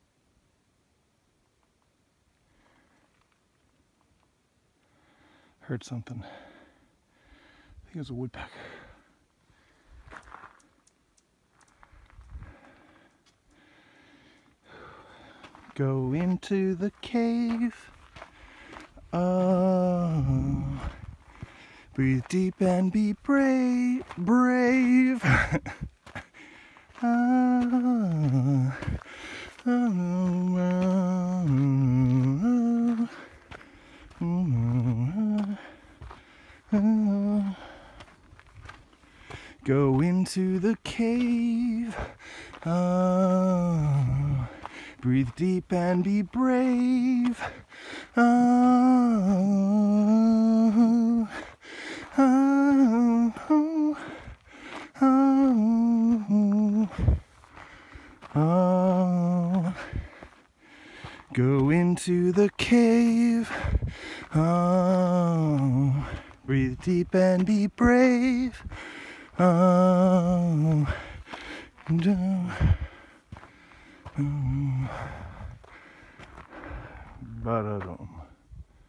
Field Recordings